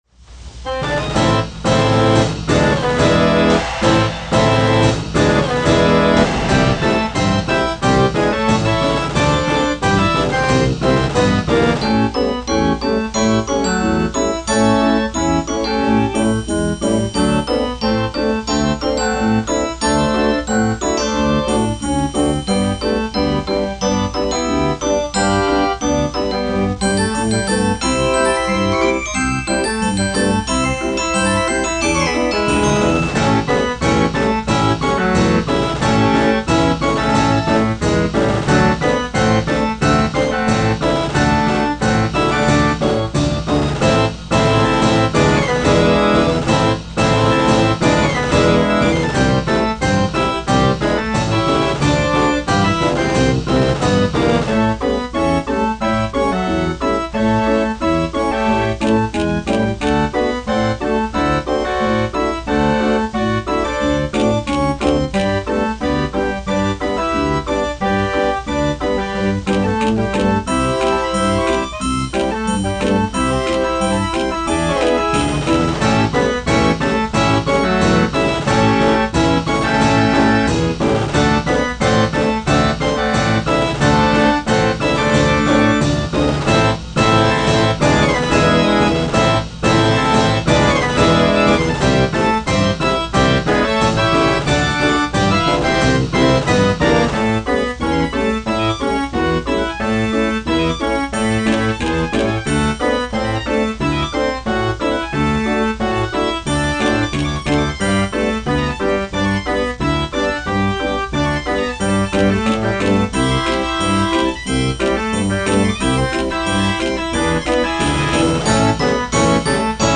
Fox Trot